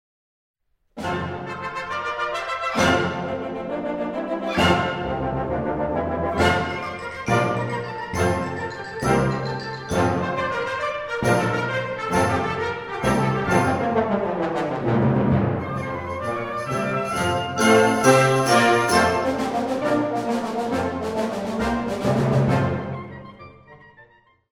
Categoria Concert/wind/brass band
Sottocategoria Suite
Instrumentation Ha (orchestra di strumenti a faito)